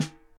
Drum Samples
S n a r e s